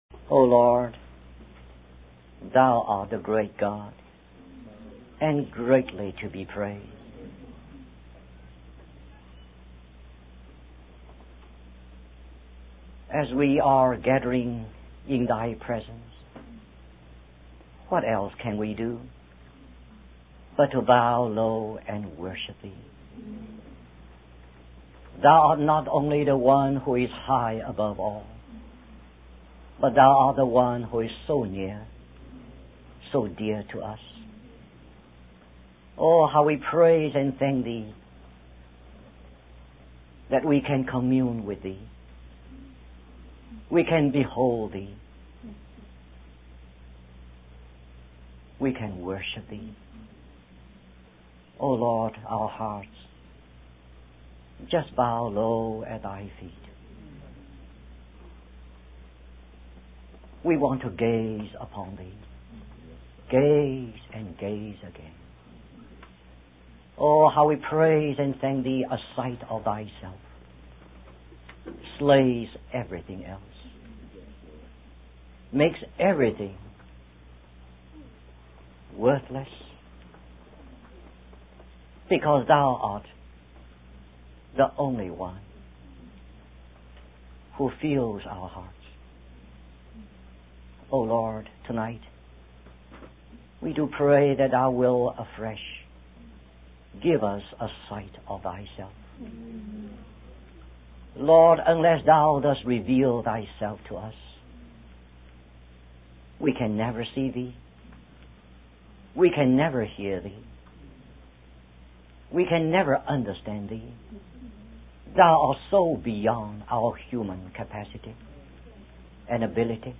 In this sermon, the speaker emphasizes the importance of not missing the main point when looking at a picture. He compares this to understanding the message of the book of Revelation. The speaker highlights the significance of the seven stars in the right hand of Jesus, representing the messengers of the seven churches.